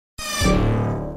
Sound Buttons: Sound Buttons View : Mortal Kombat 1 Coin Insert
coin_insert.mp3